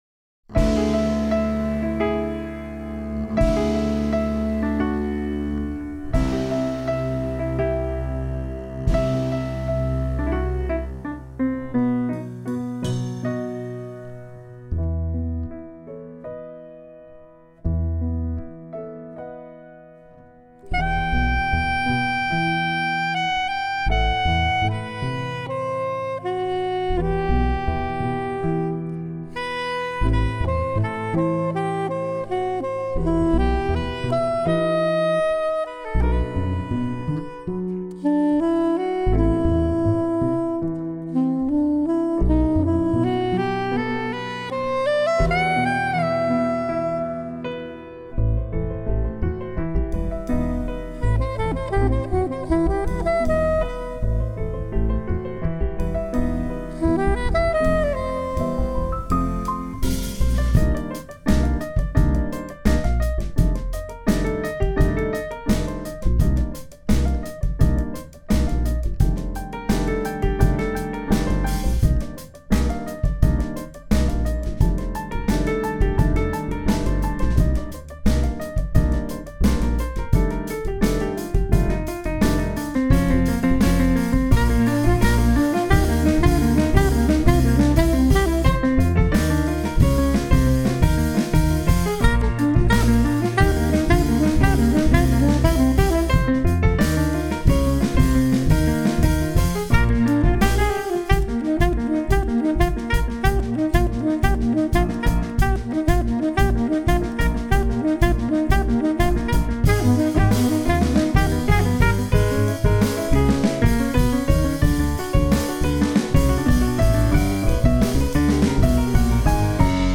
all saxes
piano, electric piano
double bass, banjo
drums